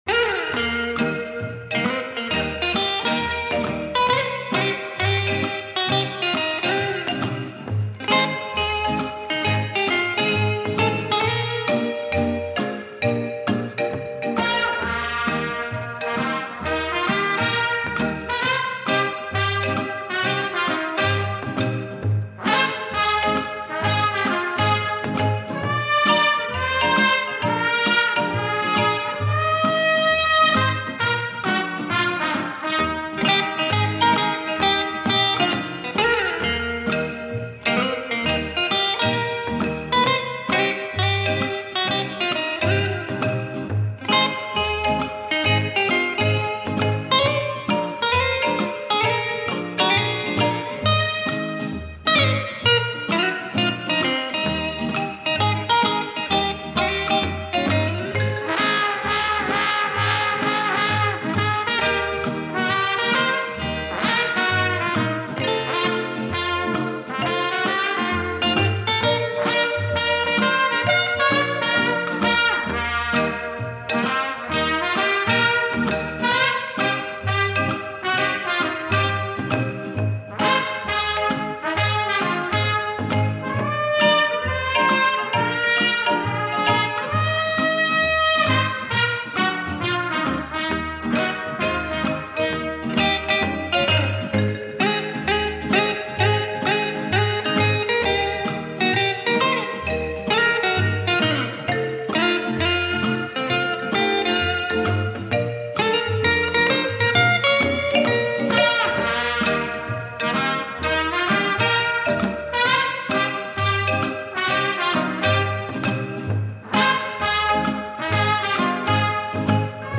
трубачом и руководителем оркестра